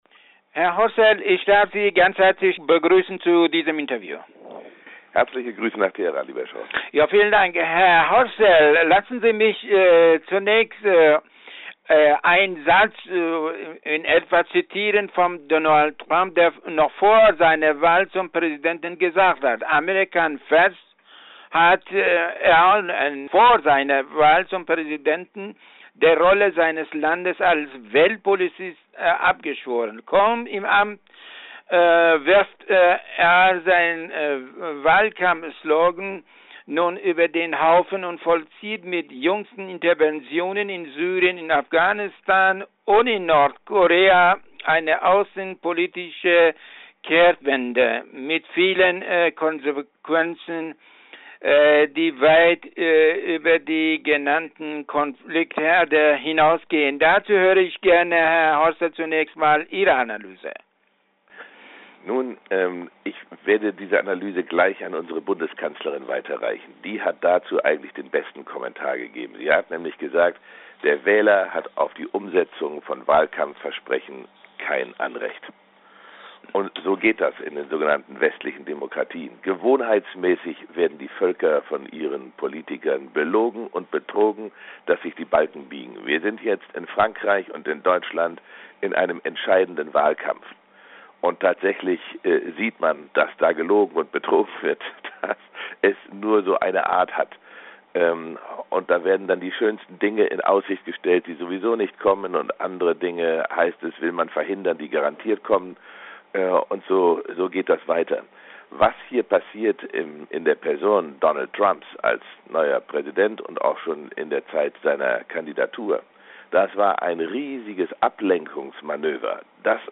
Interview in voller Länge im Audio!